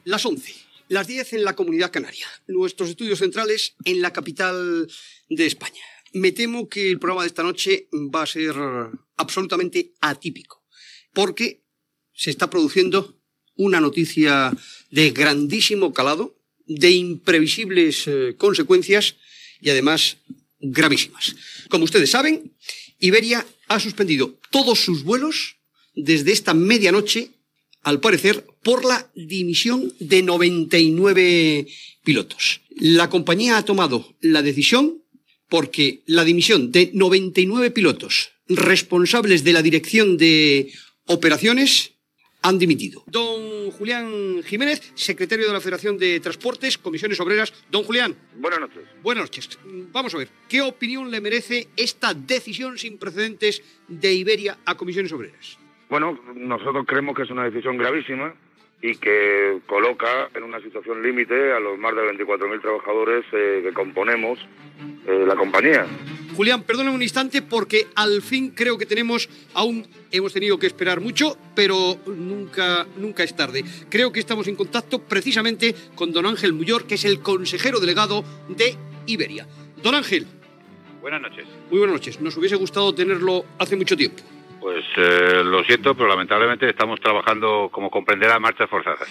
Hora, presentació del programa dedicat a la vaga dels pilots d'Iberia, diàleg entre representants sindicals.